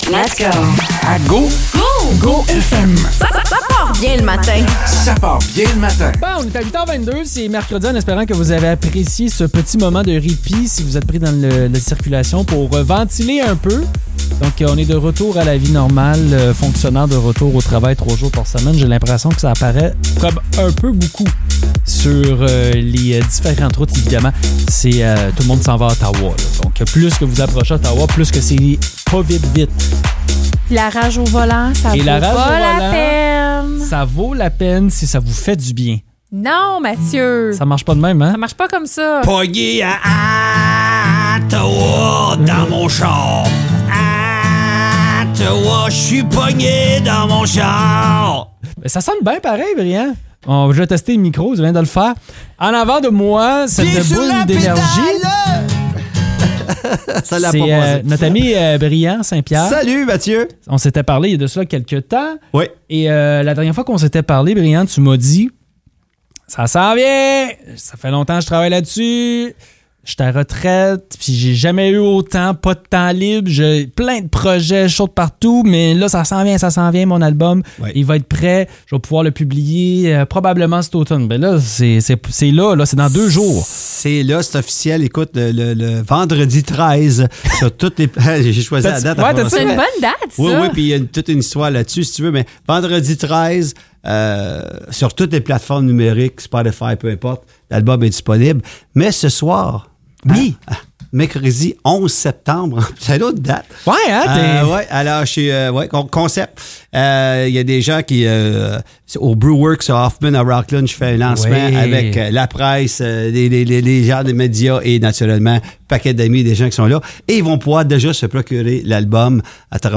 Il nous a parlé de son nouvel album et nous a offert une prestation.